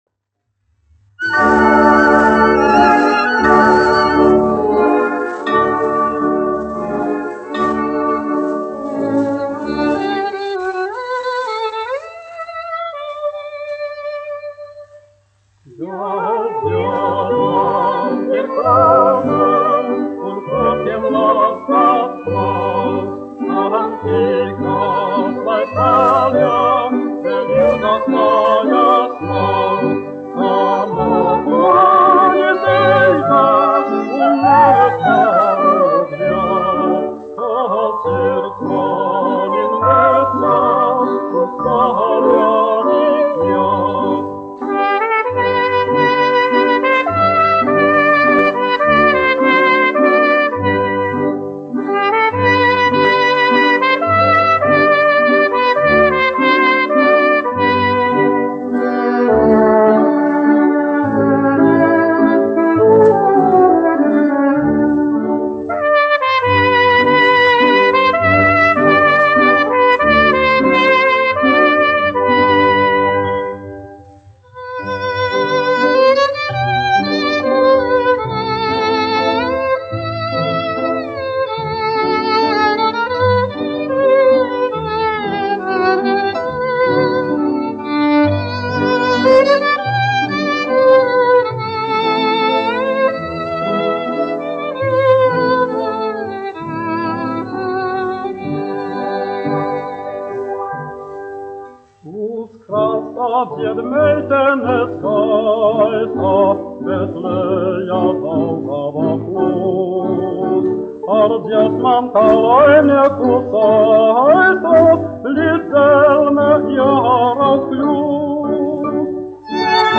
1 skpl. : analogs, 78 apgr/min, mono ; 25 cm
Popuriji
Populārā mūzika
Skaņuplate